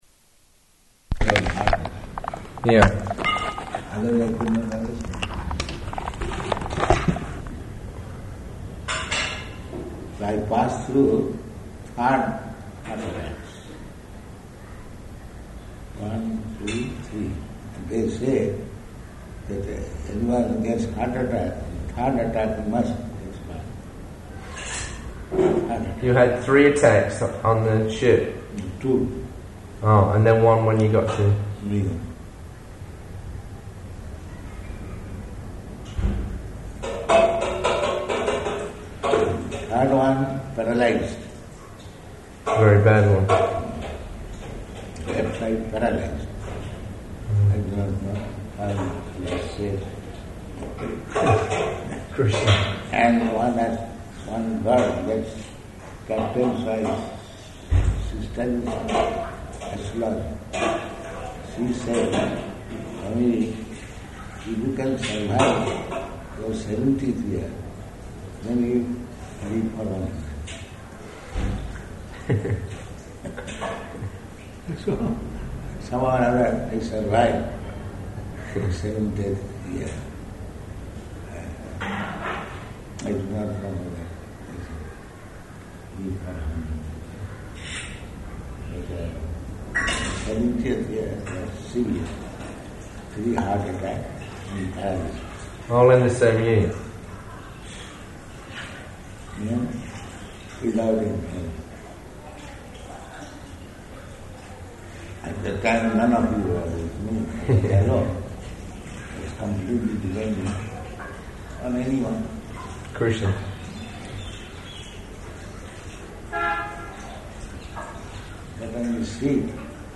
Evening Conversation